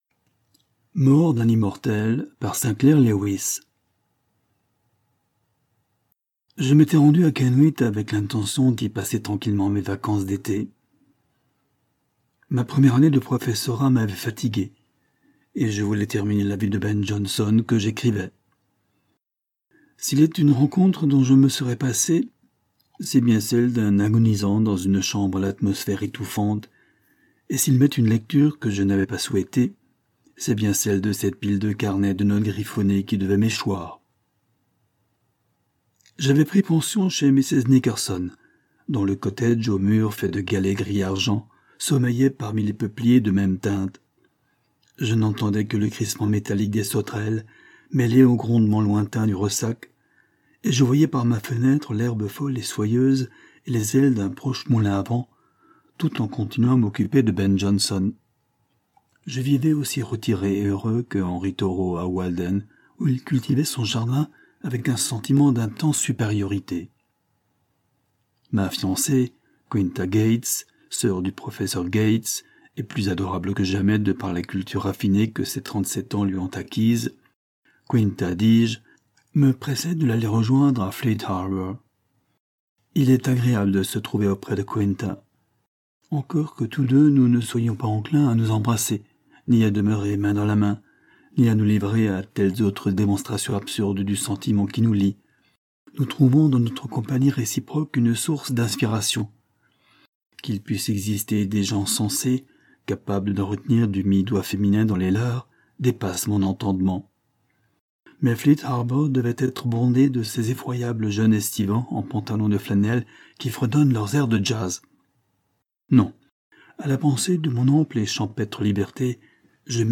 Latest was Livre audio gratuit : Mort d'un immortel.